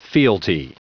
Prononciation du mot fealty en anglais (fichier audio)
Prononciation du mot : fealty